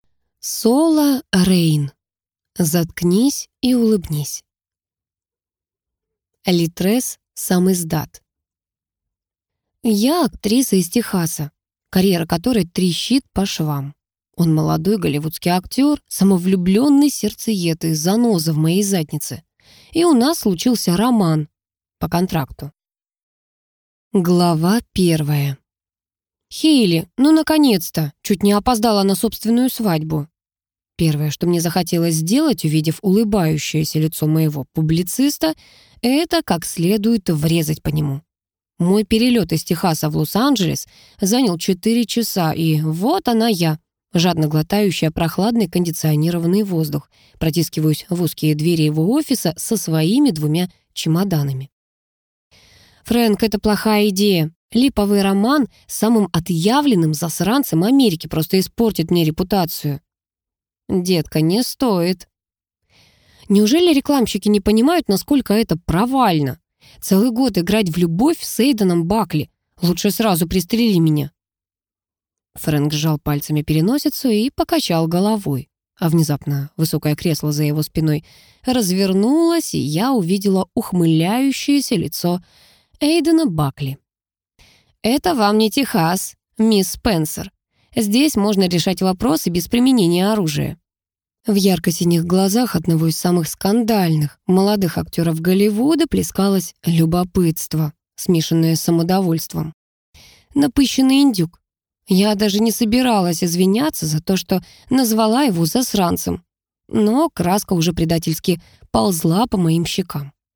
Аудиокнига Заткнись и улыбнись!